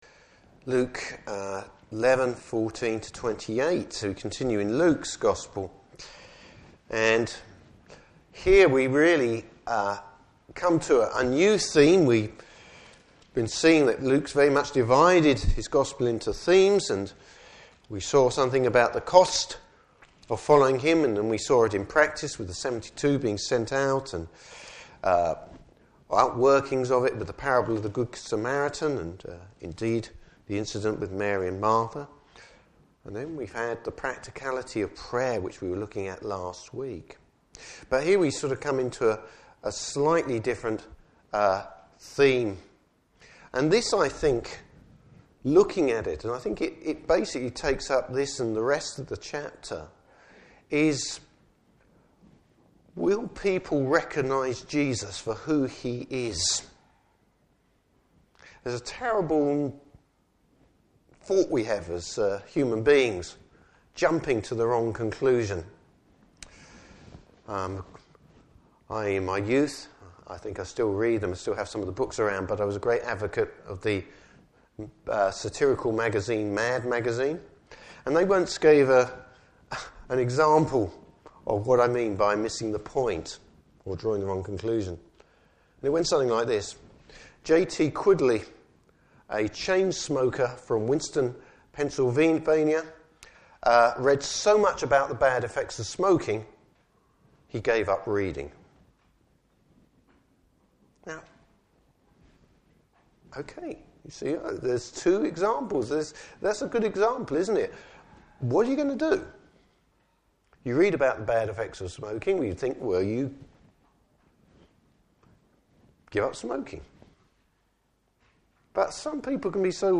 Service Type: Morning Service Bible Text: Luke 11:14-28.